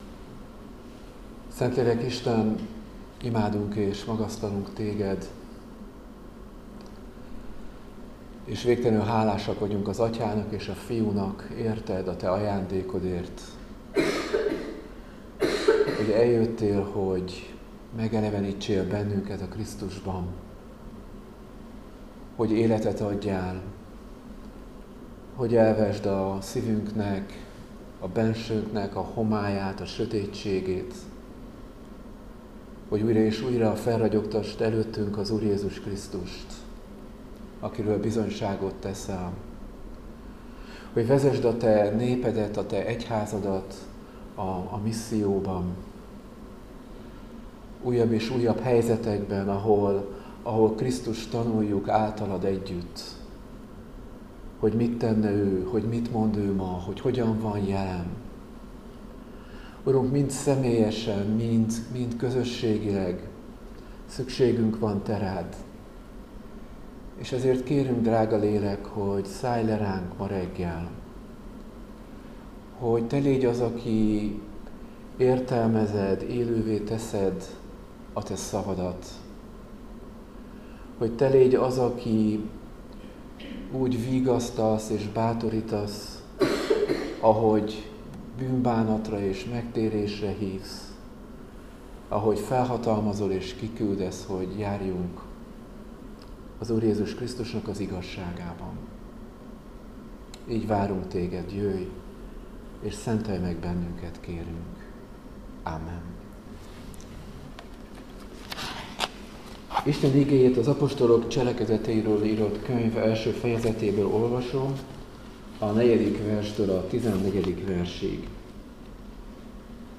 Áhítat, 2025. október 7.